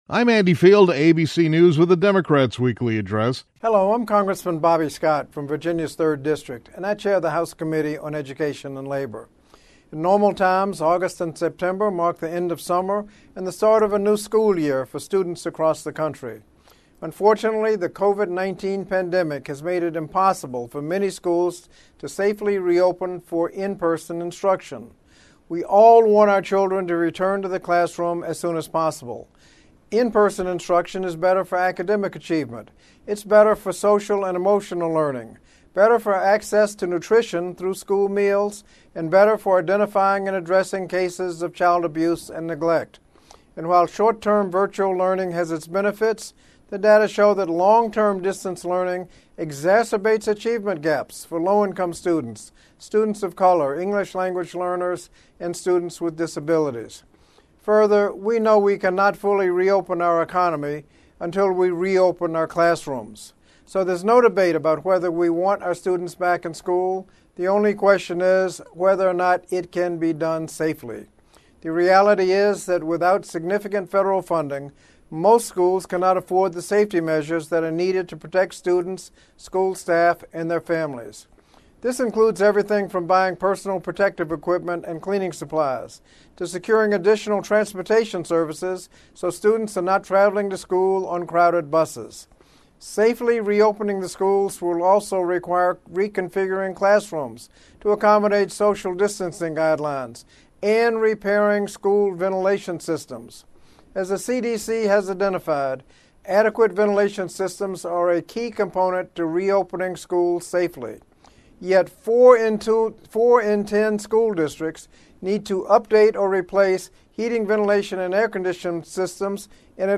During the Democratic Weekly Address, House Committee on Education and Labor Chairman Bobby Scott (D-VA) stated that without a “comprehensive” relief package, most school districts will not have the resources they need to safely reopen.